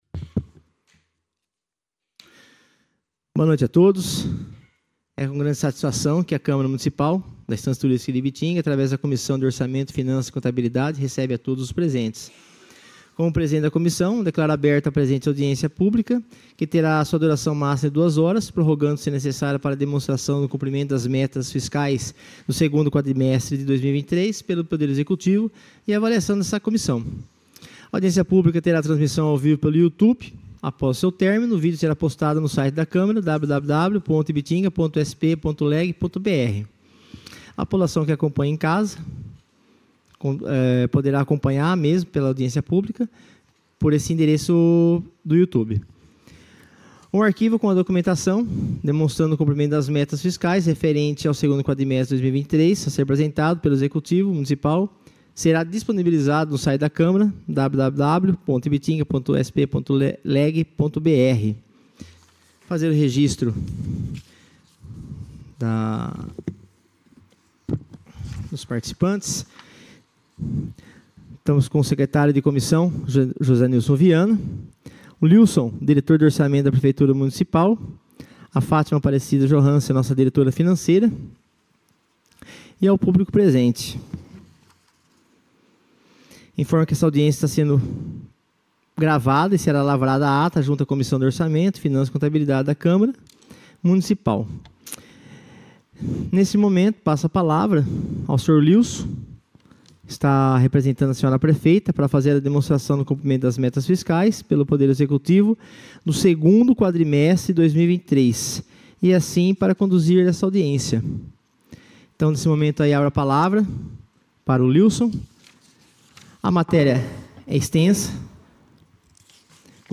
Audiências Públicas